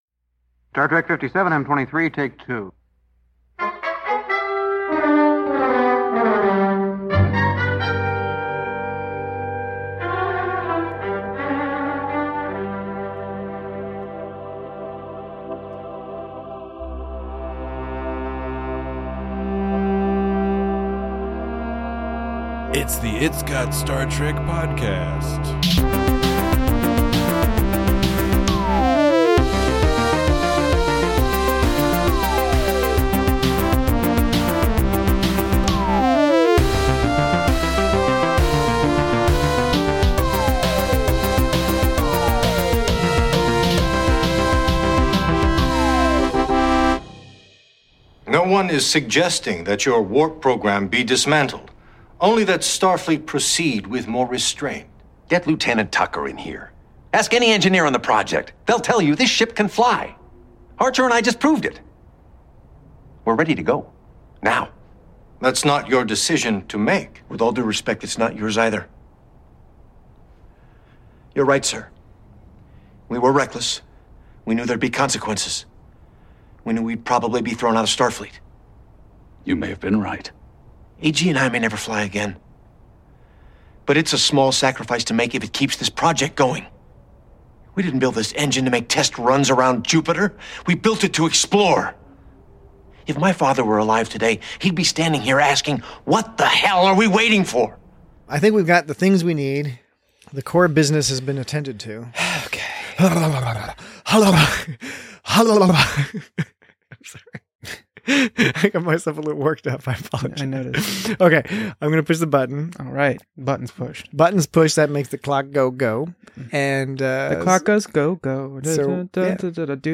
Captain Archer learns that the ends always justify the means. Join your pioneering hosts as they discuss tightly-plotted character development, expert casting, and charming performances in the much beloved antepenultimate episode to Star Trek: Enterprise's second season.